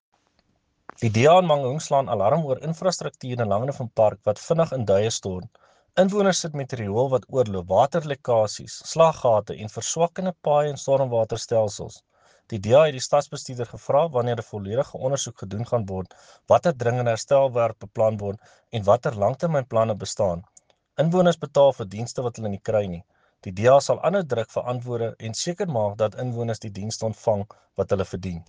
Afrikaans soundbites by Cllr Jan-Hendrik Cronje and